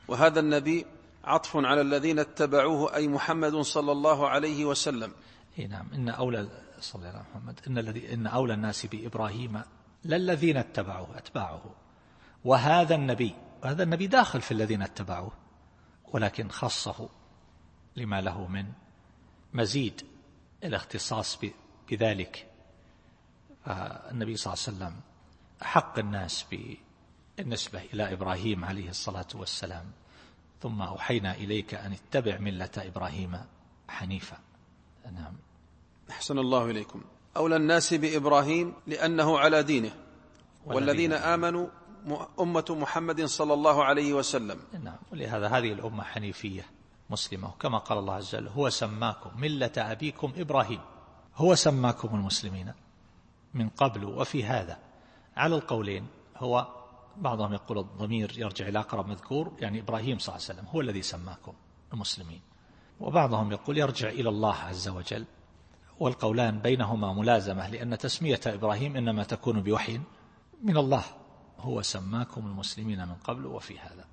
التفسير الصوتي [آل عمران / 68]